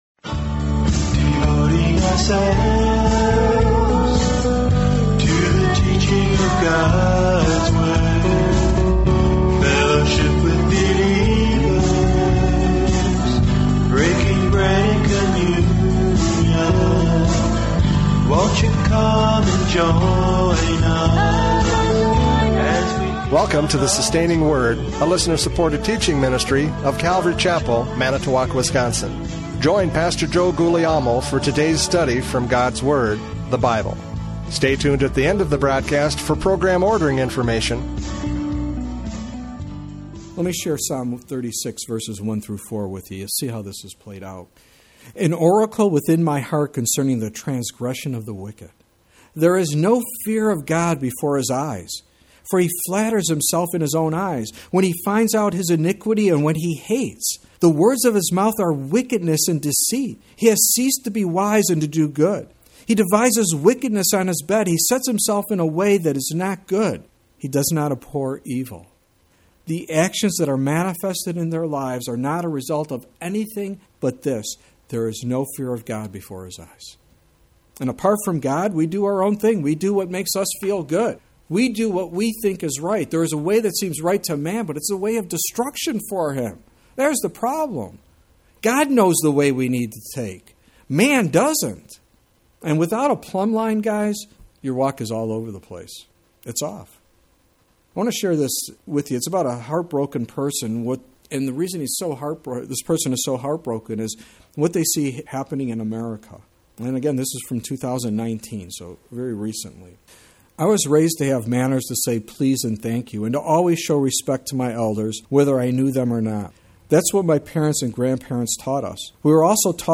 Judges 14:1-4 Service Type: Radio Programs « Judges 14:1-4 No Respect!